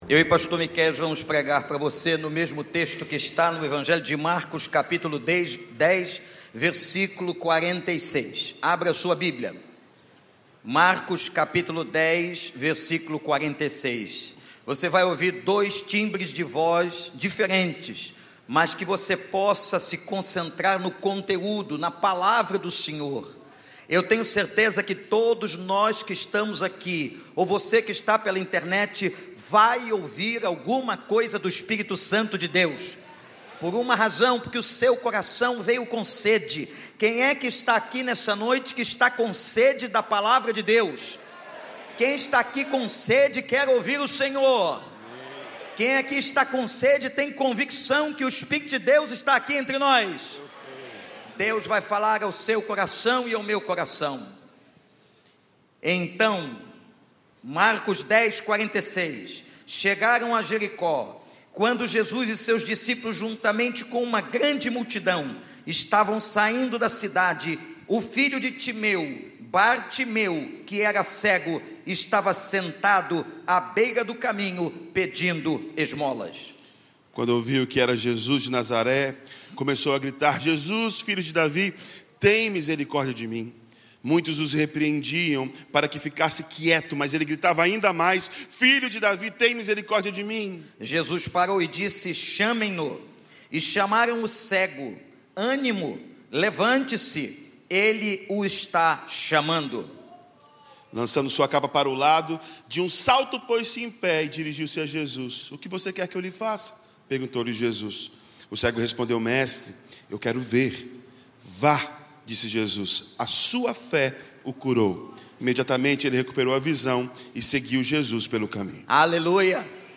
Mensagem
na Igreja Batista do Recreio.